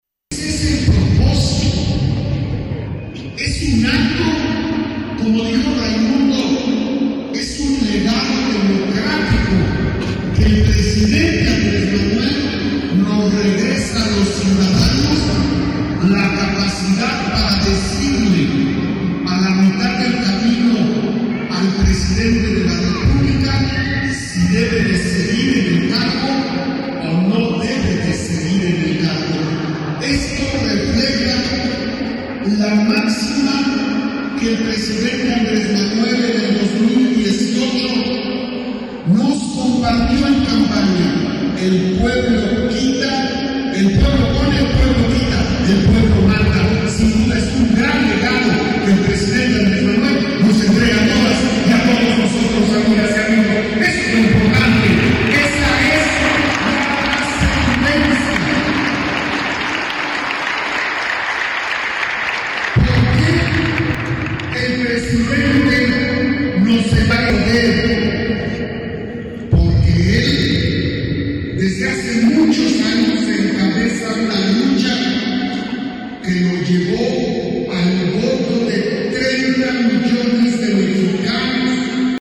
Senador hablando sobre la Consulta Popular